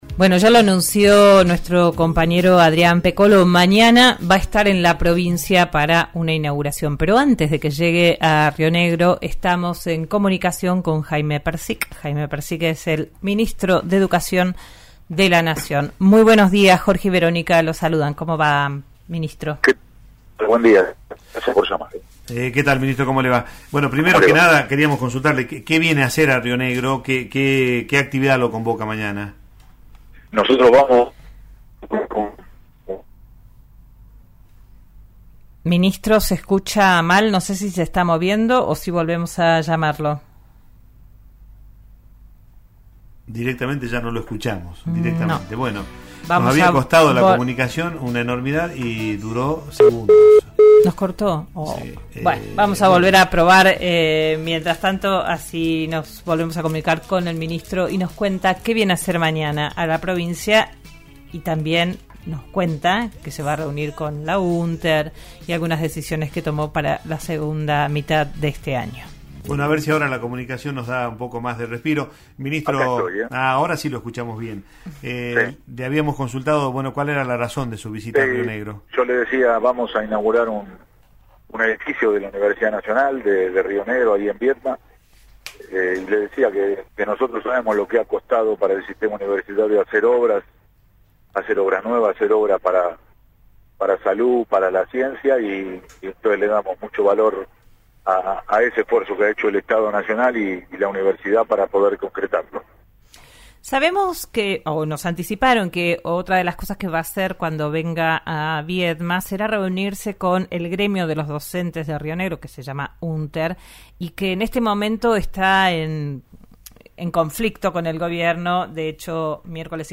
Jaime Perczyk, ministro de Educación de la Nación, dialogó esta mañana con Digan lo que Digan, el programa de RN Radio. En una charla telefónica anticipó que visitará la provincia el martes para una inauguración en la sede de Viedma de la Universidad Nacional de Río Negro. En ese contexto también confirmó que se reunirá con el gremio docente de la Unter.